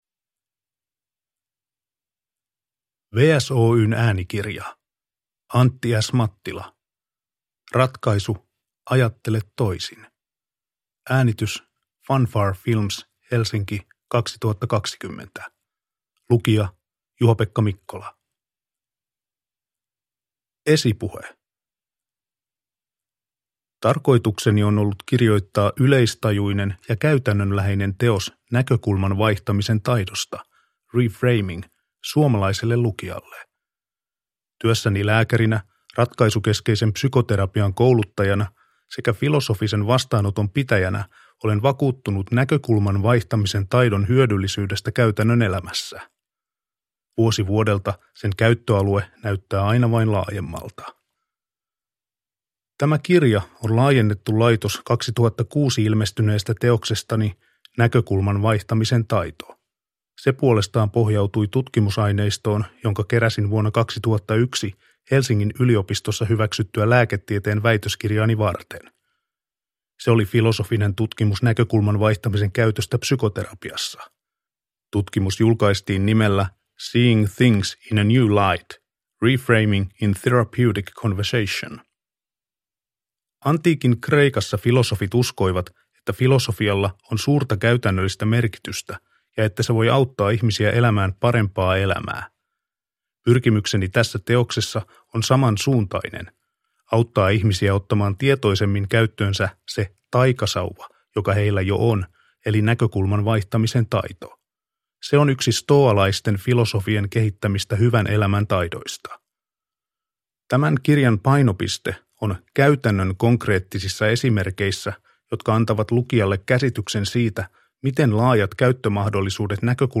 Ratkaisu: Ajattele toisin – Ljudbok – Laddas ner